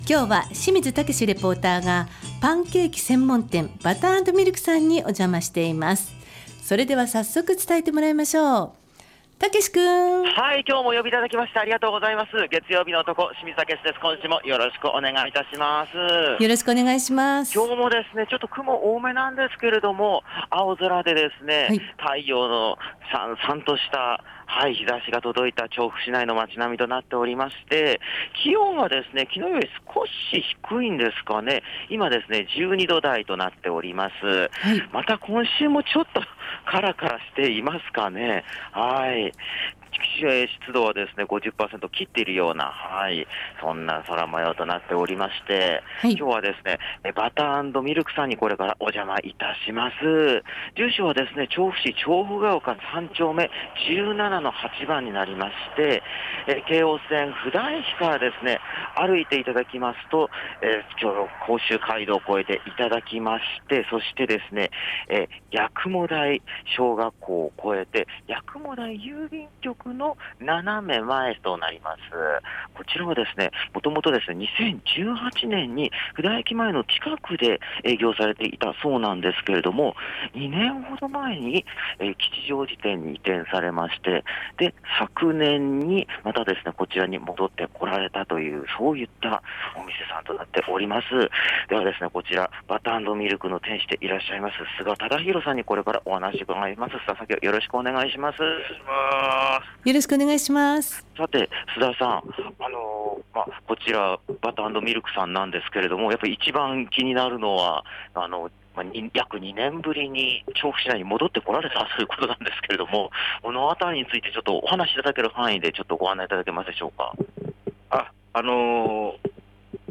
桜の開花が迫るなかからお届けした街角レポートは、布田駅から徒歩10分弱ほどのパンケーキ専門店バター＆ミルクさんからのレポートです。